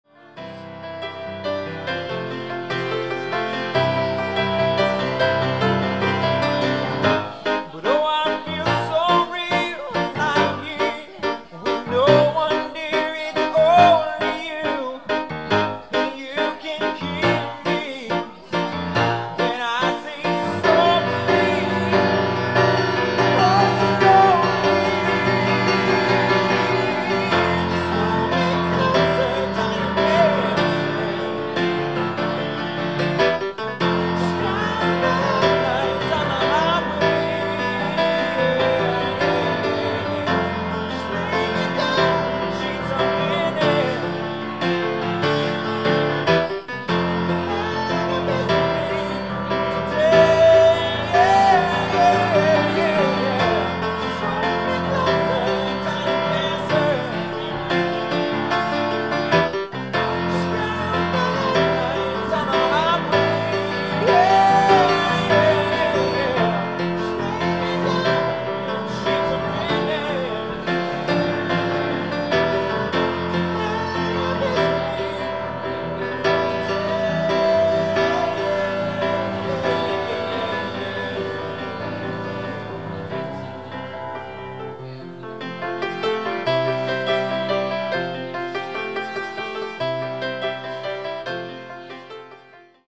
live at Gianna's, NYC